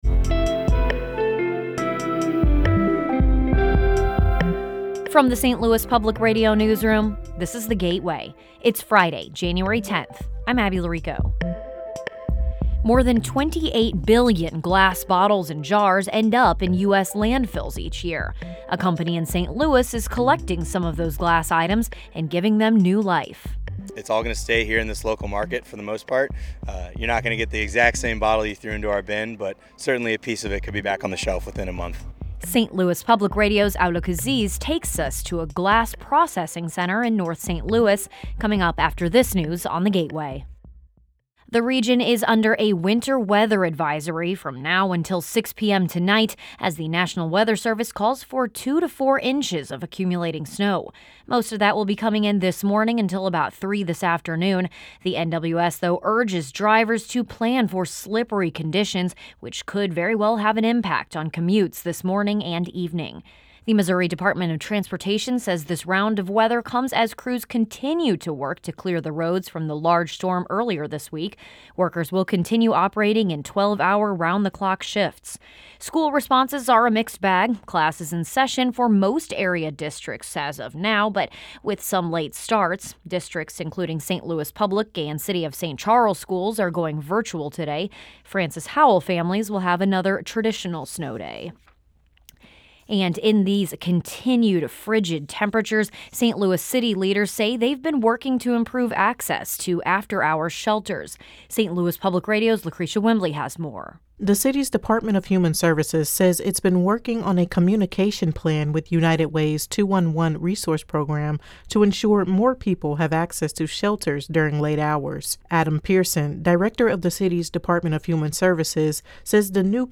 … continue reading 1421 afleveringen # News # St Louis Public Radios